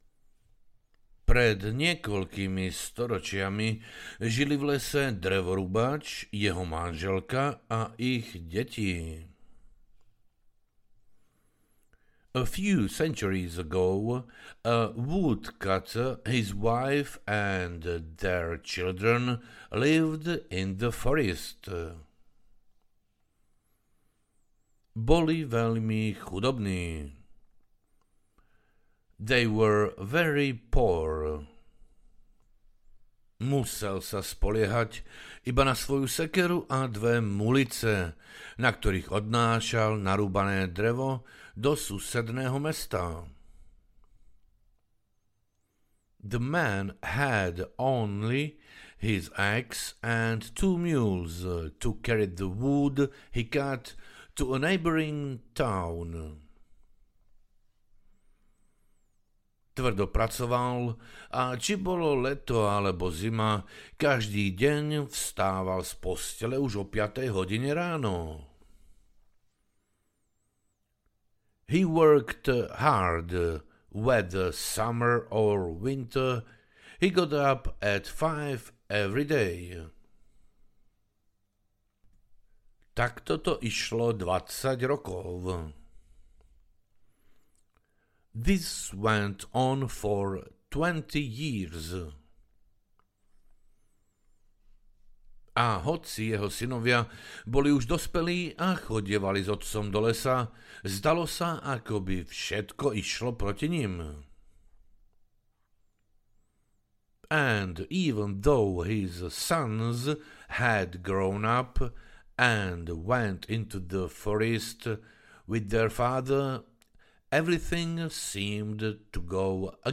Ukázka z knihy
Pretože každá veta je citovaná v slovenskom jazyku a potom preložená do angličtiny.
Pritom sa vychádzalo z britskej angličtiny.
The audiobok is based on British English.